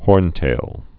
(hôrntāl)